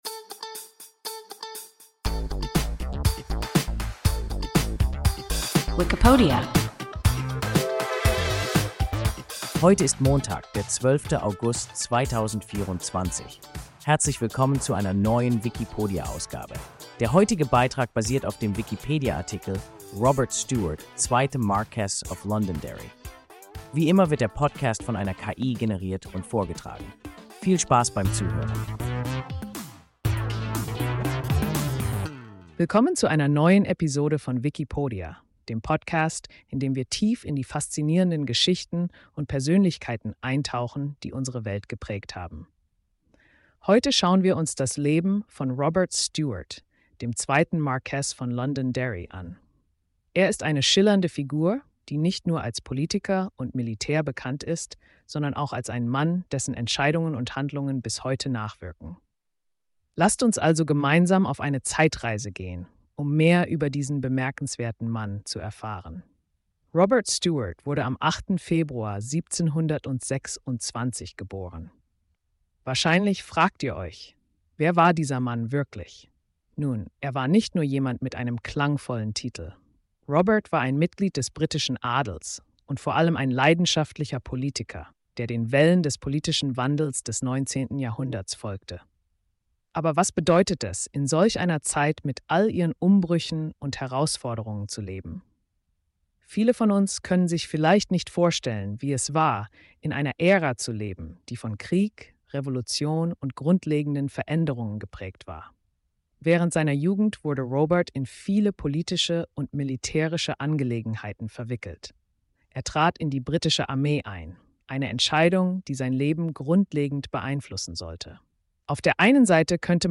Marquess of Londonderry – WIKIPODIA – ein KI Podcast